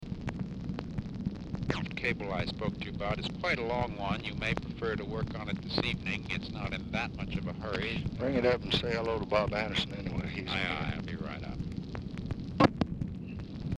Telephone conversation # 8118, sound recording, LBJ and MCGEORGE BUNDY, 6/10/1965, 7:03PM | Discover LBJ
RECORDING STARTS AFTER CONVERSATION HAS BEGUN
Format Dictation belt
Location Of Speaker 1 Oval Office or unknown location